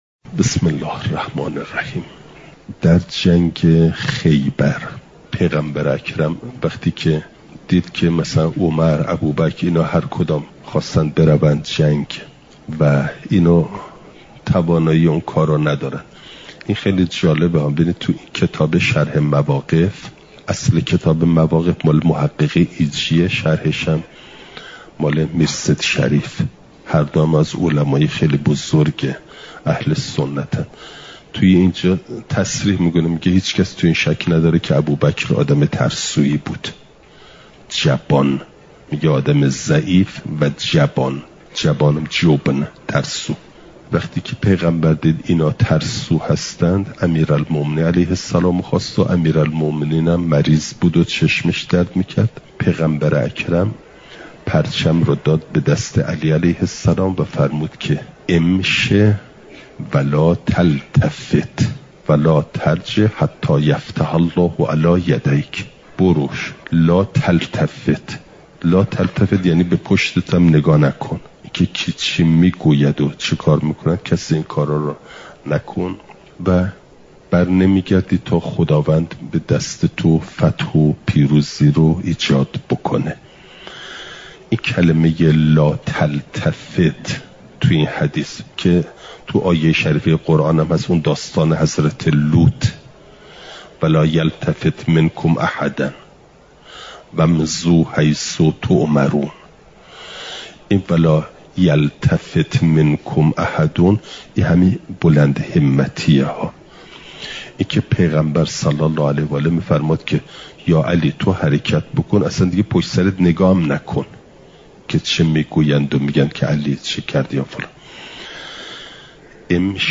چهارشنبه ۲۱ آبانماه ۱۴۰۴، حرم مطهر حضرت معصومه سلام ﷲ علیها